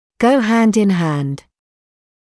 [gəu hænd ɪn hænd][гоу хэнд ин хэнд]идти рука об руку, идти вместе